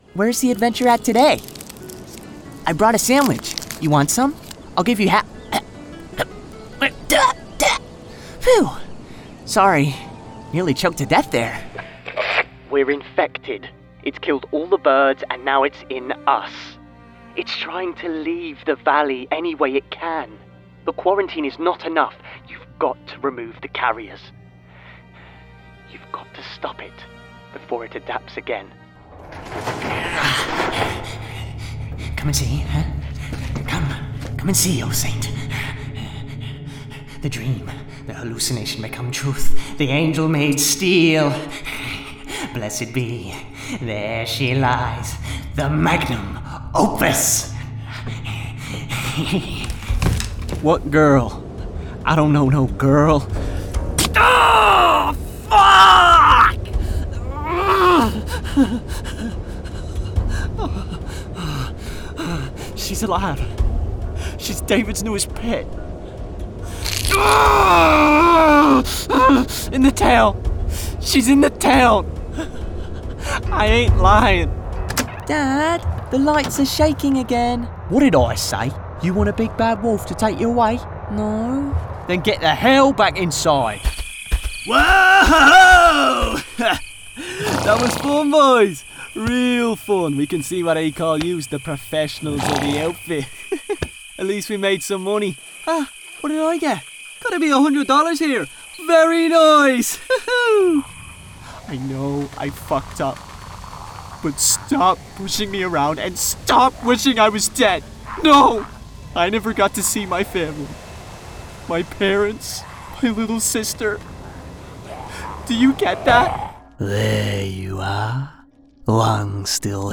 with a warm and youthful sound.
Gaming